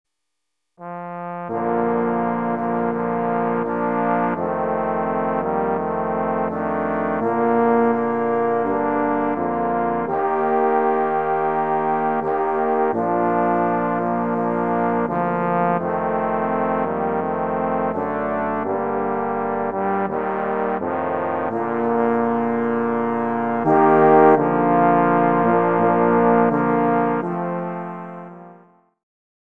【編成】トロンボーン四重奏（3 Tenor Trombone and Bass Trombone）
スコットランド民謡